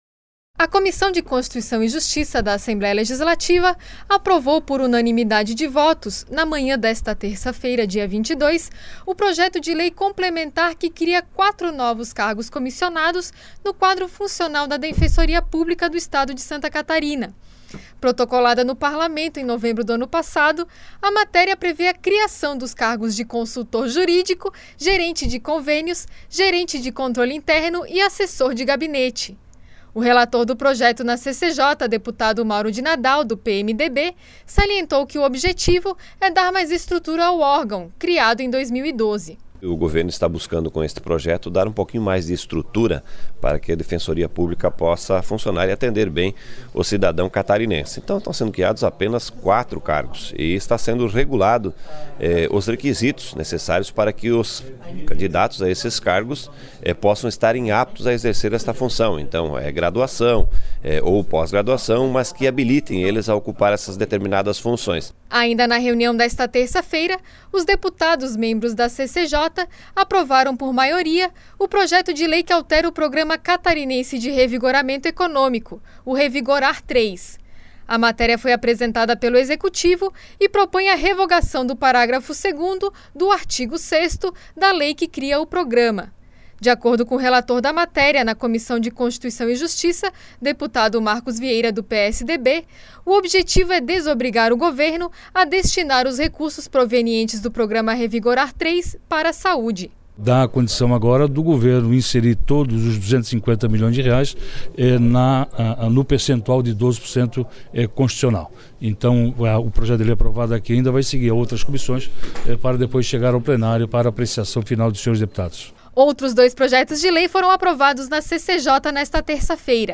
Entrevistas: Deputado Mauro de Nadal (PMDB); Deputado Marcos Vieira (PSDB), presidente da Comissão de Constituição e Justiça.